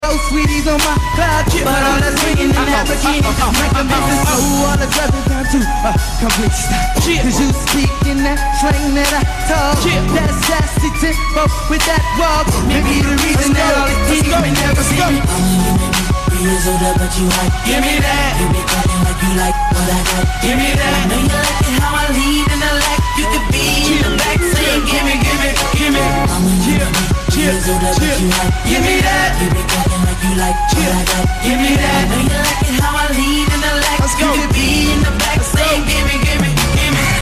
BET Awards 2006